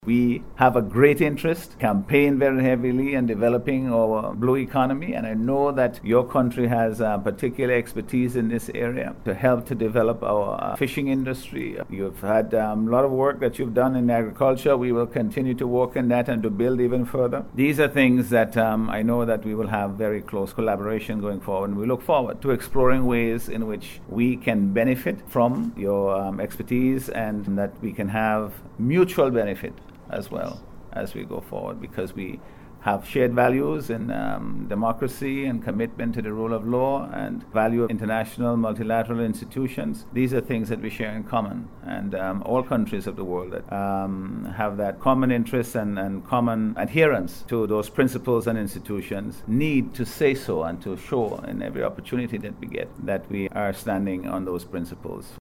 He made this statement at a recent handover ceremony, at which St. Vincent and the Grenadines received an EC$8 million grant from the Republic of China (Taiwan) to strengthen social support for the country’s most vulnerable.